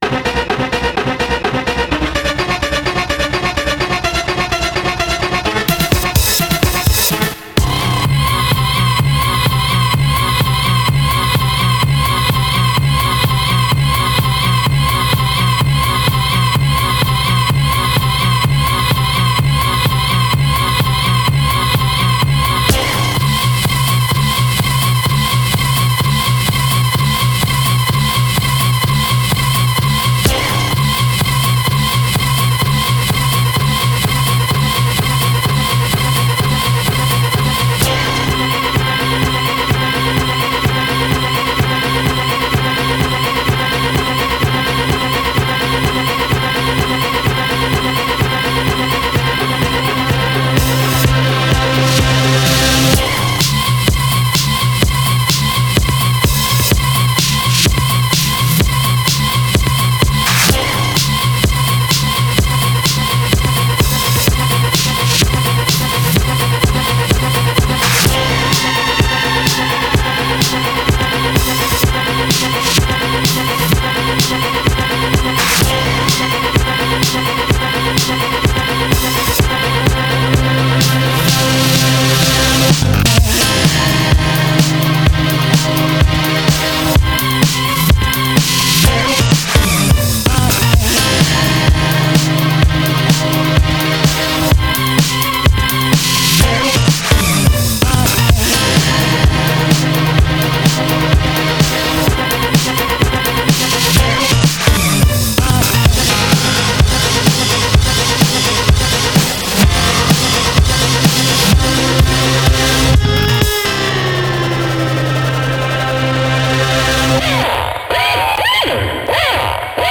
Just some thinking music